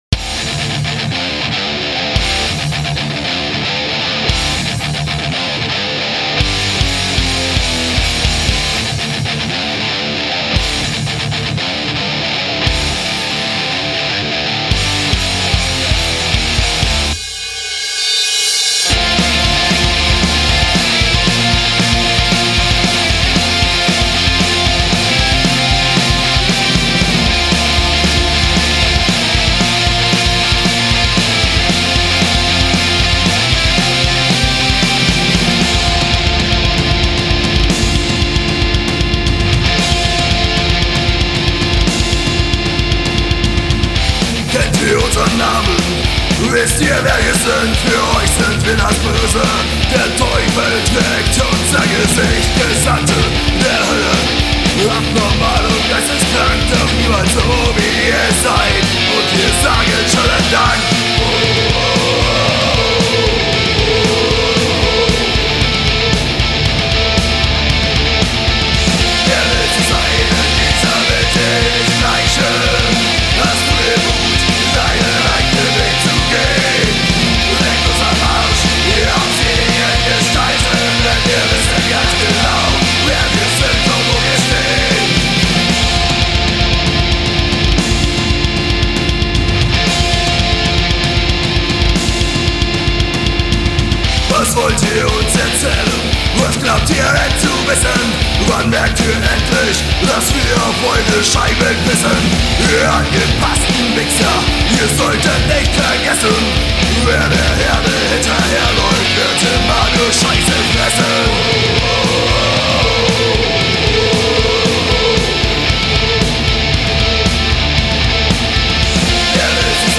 Deutschrock im Miniformat…